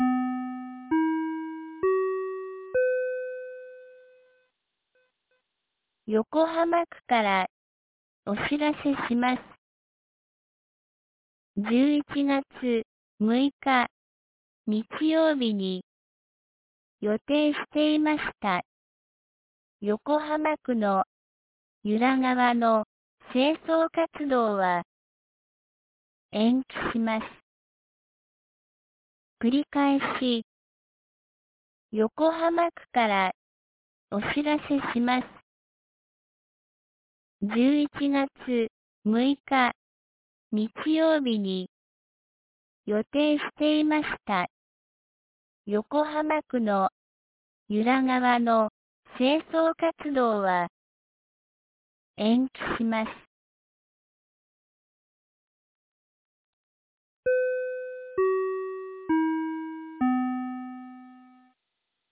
2022年11月04日 17時12分に、由良町から横浜地区へ放送がありました。